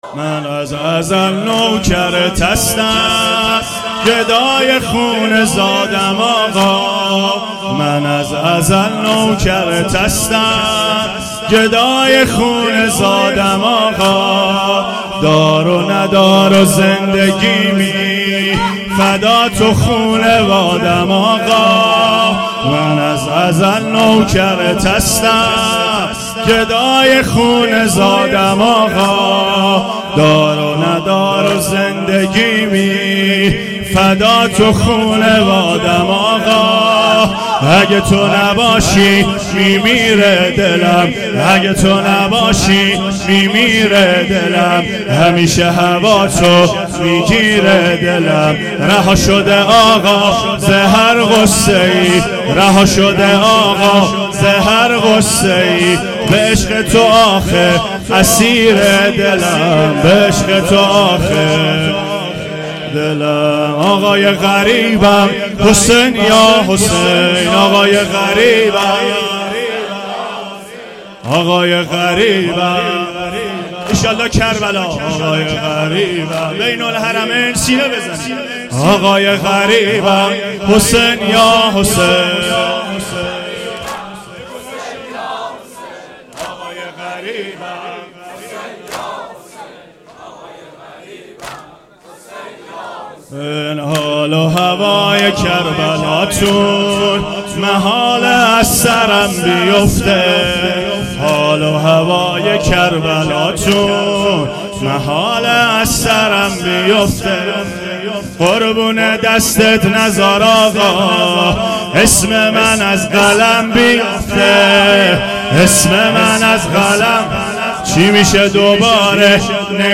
واحد تند شب پنجم فاطمیه